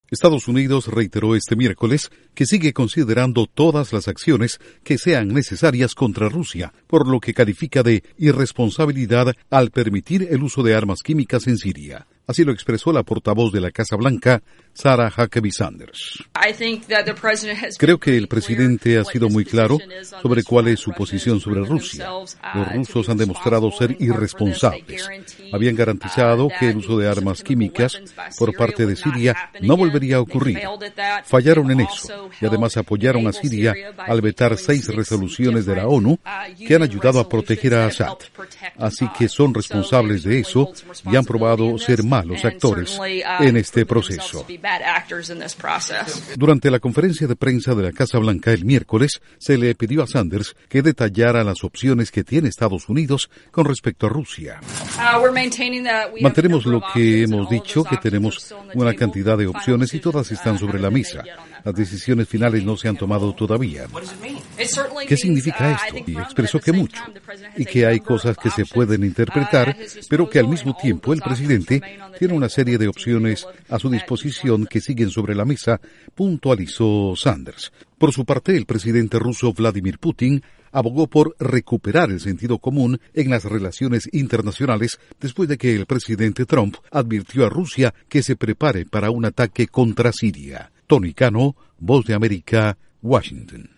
3 audios de Sarah H. Sanders/Portavoz de la Casa Blanca